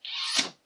A Slide Pop Sound
A strange sound effect with a slide and then a pop - maybe a cork, maybe a missile launch? who knows.
slidepop_0.mp3